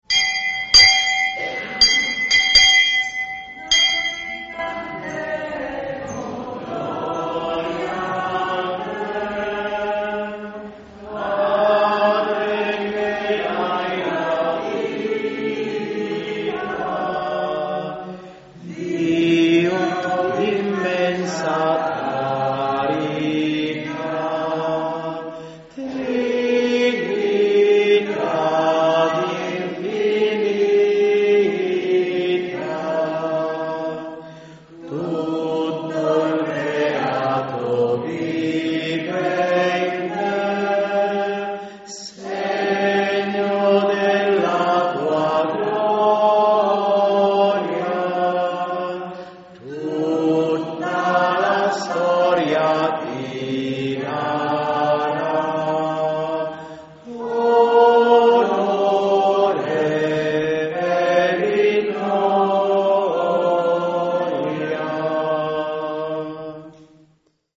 Festa Patronale di San Martino